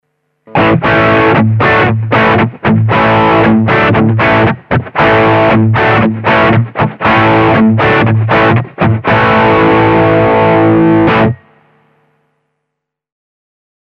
The P-90
A wider coil isn’t able to pick up high frequencies (treble) as well as a narrow one, which is why a P-90 generally has a more middly tone than a Fender-singlecoil:
LP Junior drive
junior-drive.mp3